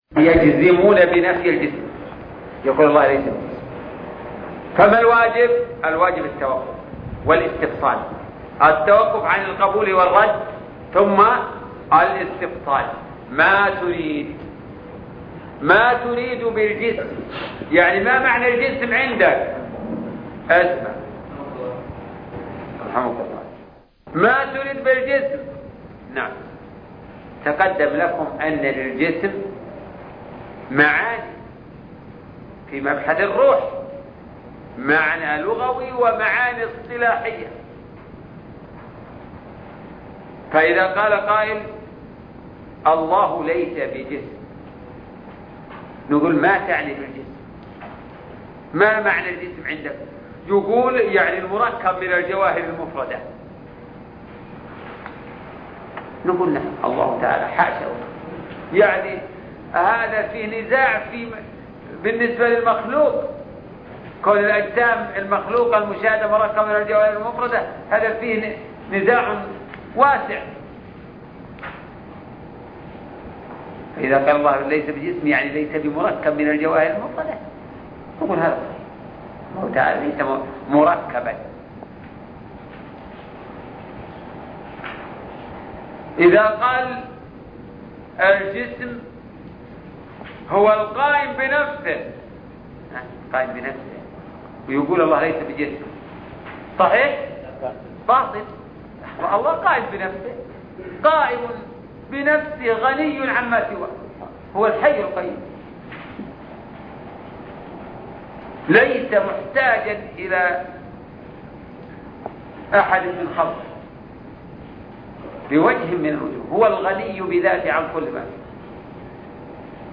شرح الرسالة التدمرية (19) الدرس التاسع عشر